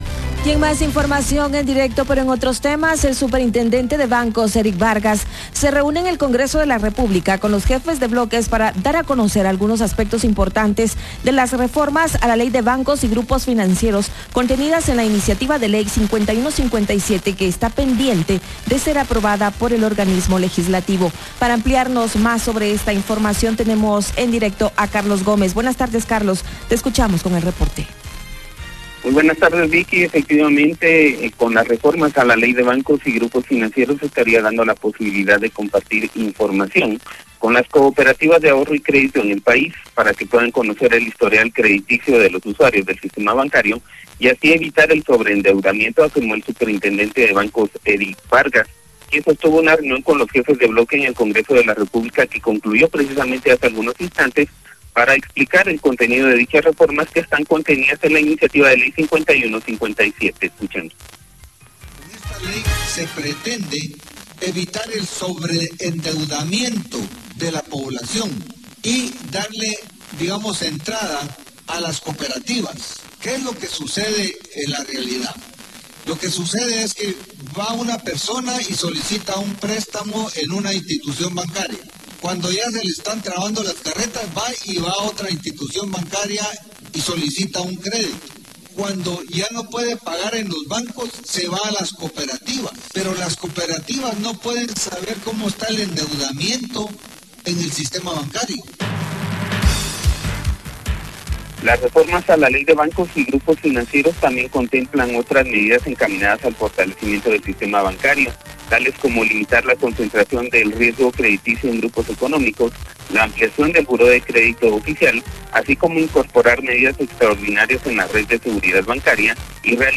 Noticias Iniciativa de Ley 5157